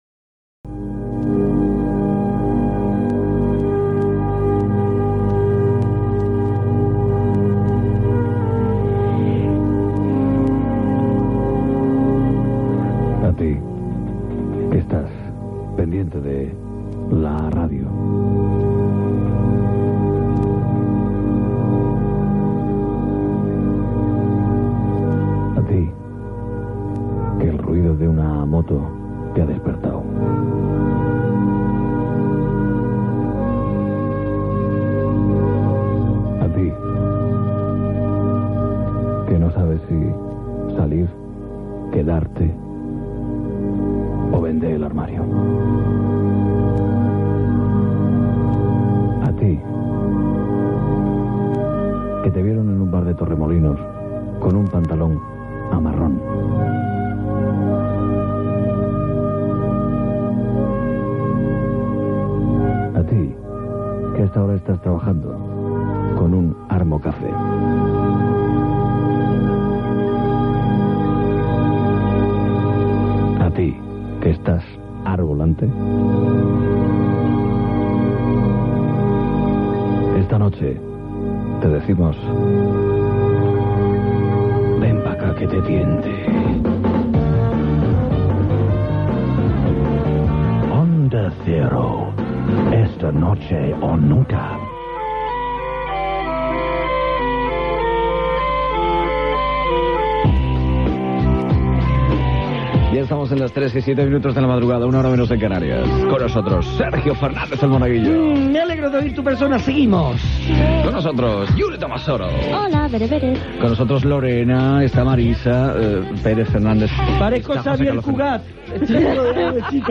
Invitació a l'audiència, esment de les persones que participen en aquella hora del programa, proposta de participació a l'audiència en "El confesionario", tema musical, trucada d'un camioner Gènere radiofònic Entreteniment